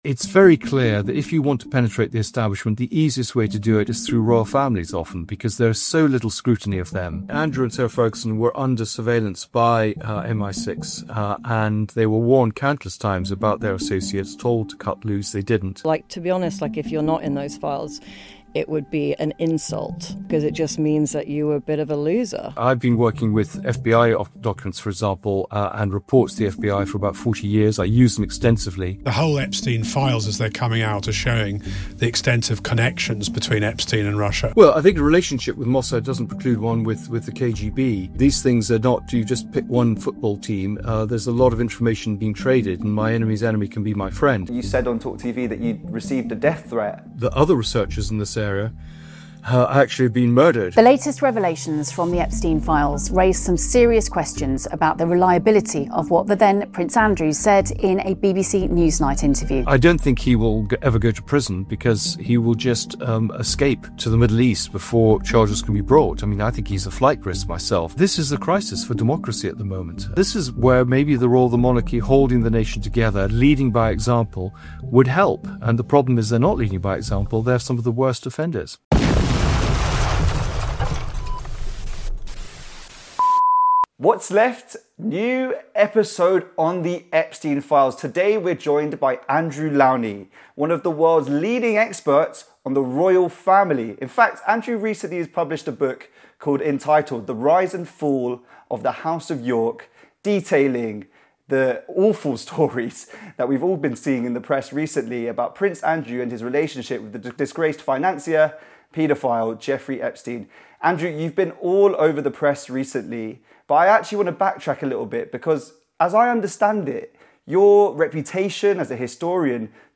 It documents arguably the greatest crisis to grip the British Royal Family, following claims of corruption, criminal associations and cover ups. In this interview, we explore the wider ramifications of the Epste1n files on the state of liberal democracy.